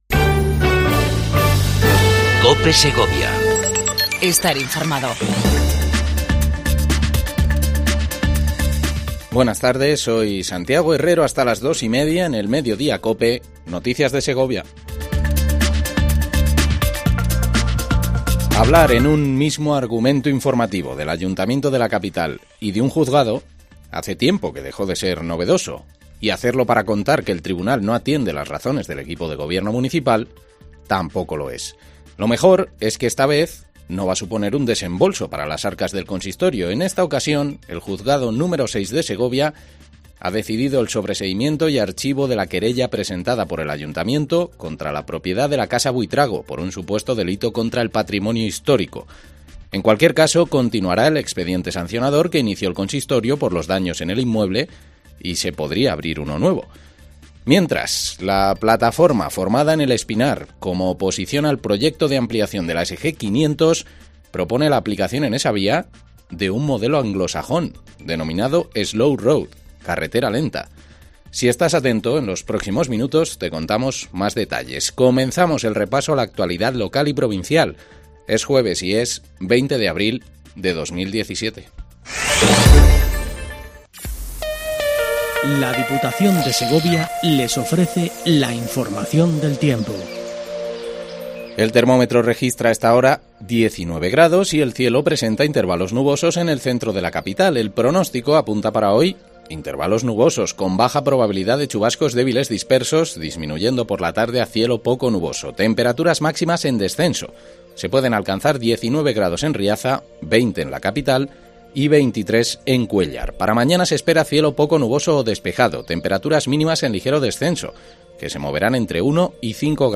INFORMATIVO MEDIODIA COPE EN SEGOVIA 20 04 17